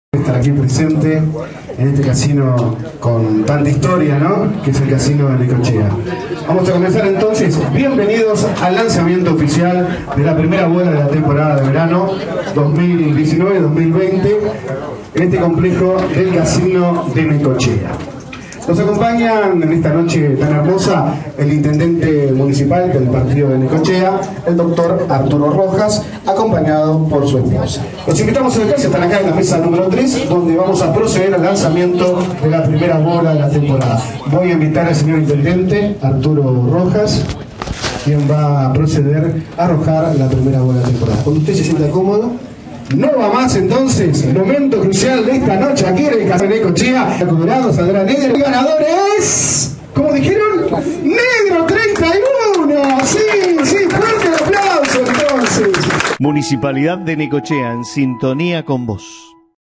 El acto se desarrolló en la sala de juegos del Complejo Casino local, más precisamente en la mesa N° 3, ante la atenta mirada de un nutrido grupo de vecinos y turistas.